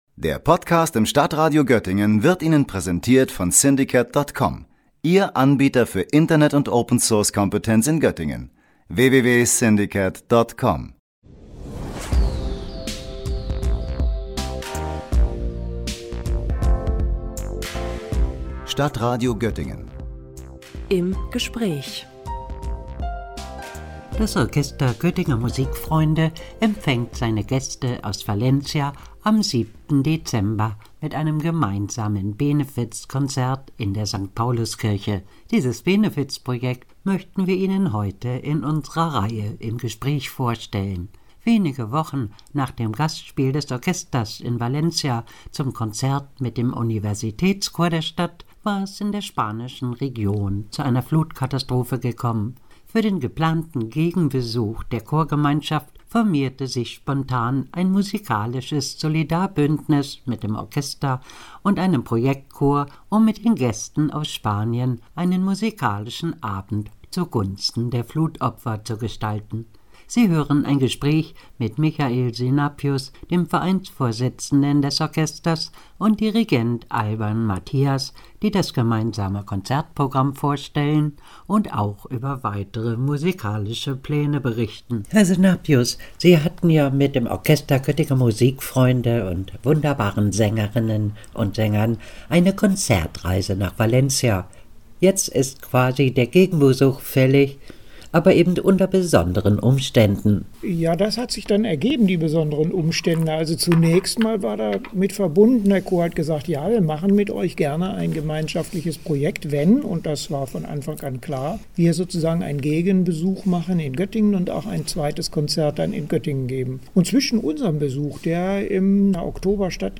Benefizkonzert des Orchesters Göttinger Musikfreunde zugunsten der Flutopfer in Valencia – Gespräch